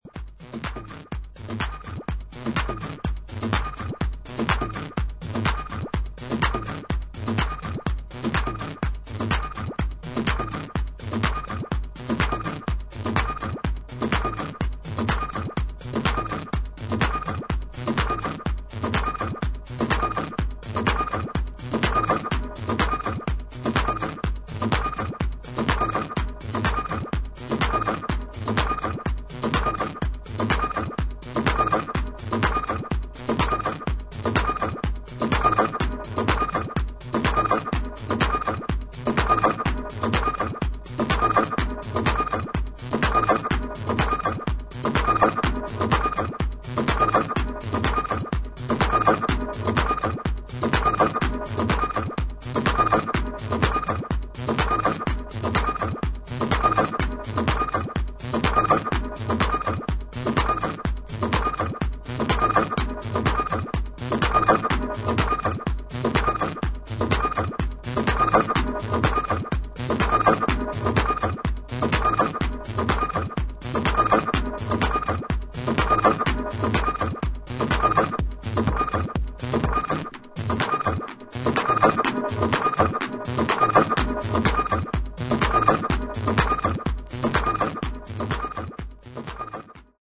ハードウェアサウンドの面白さを堪能できる一枚です。